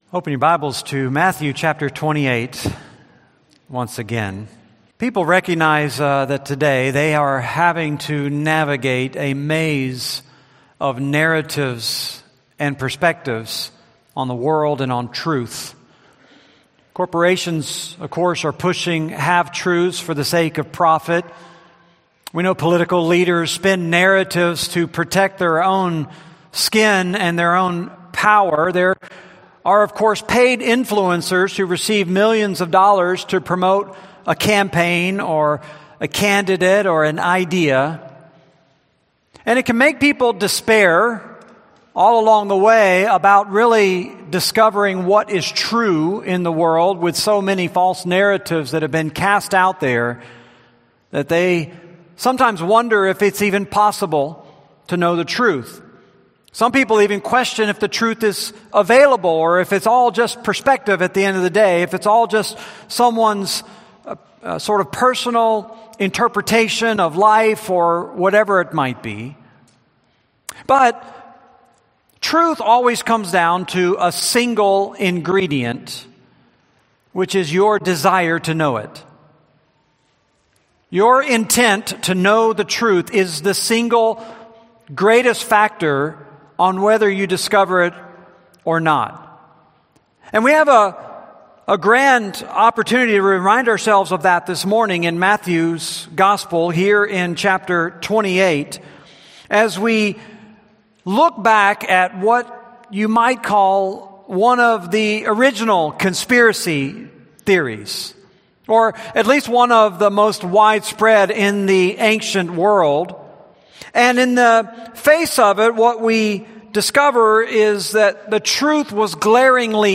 Series: Matthew, Sunday Sermons